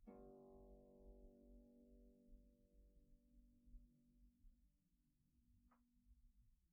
KSHarp_G1_mp.wav